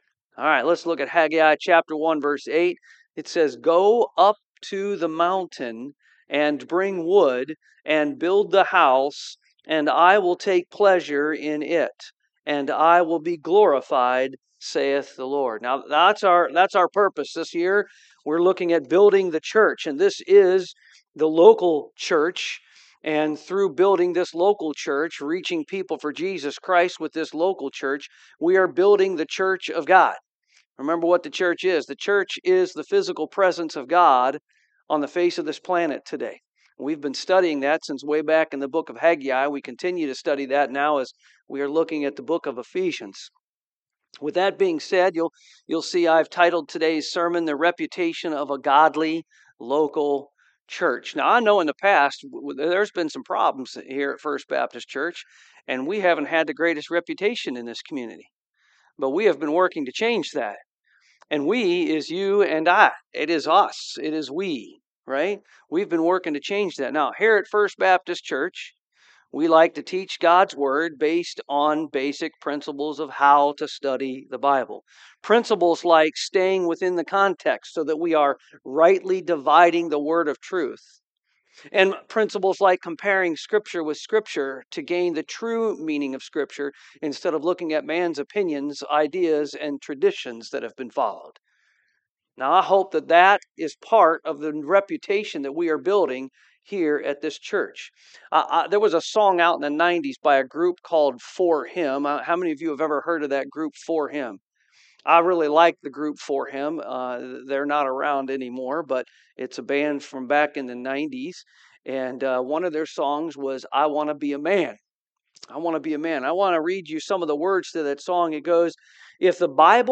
Service Type: AM